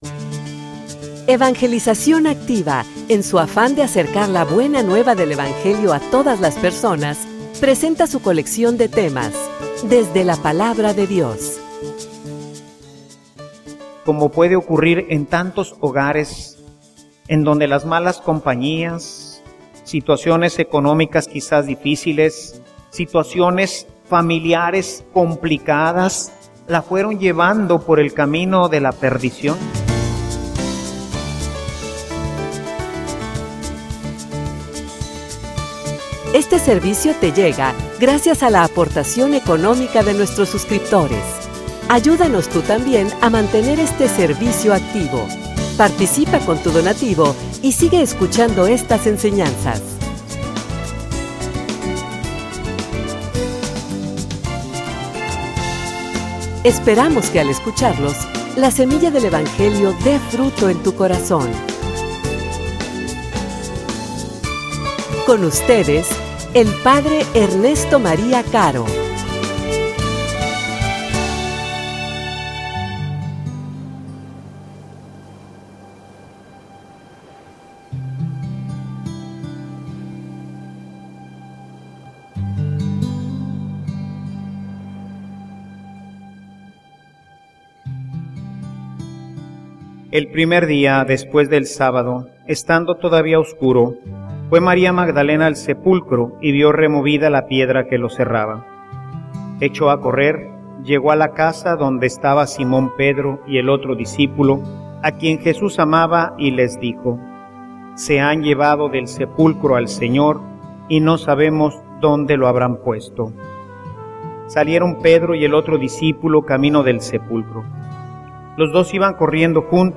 homilia_Un_encuentro_con_mi_amado.mp3